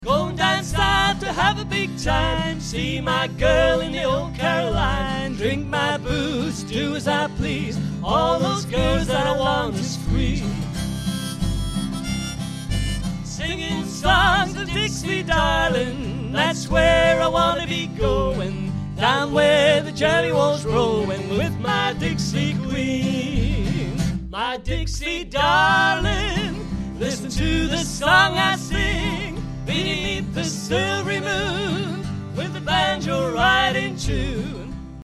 double bass
guitar and harmonica